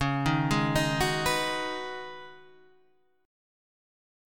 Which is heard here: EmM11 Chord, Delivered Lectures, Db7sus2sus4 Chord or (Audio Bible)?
Db7sus2sus4 Chord